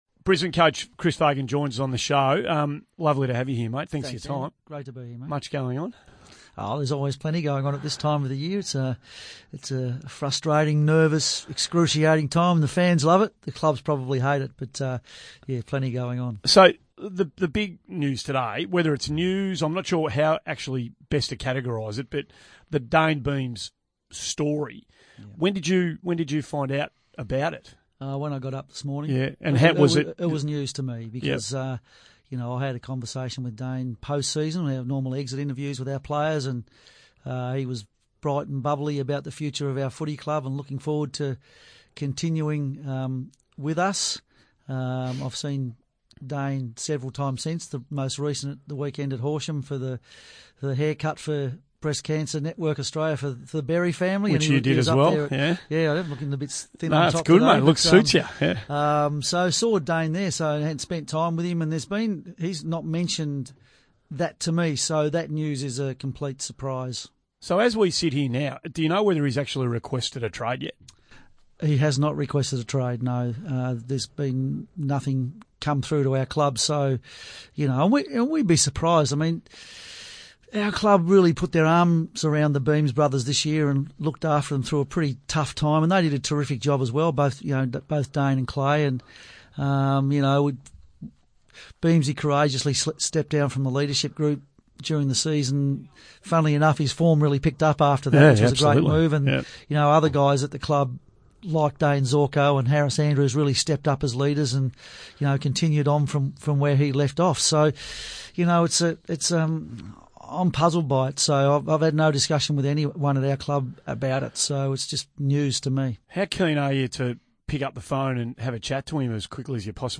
Chris Fagan speaks with Andy Maher on SEN regarding the Dayne Beams news earlier this morning.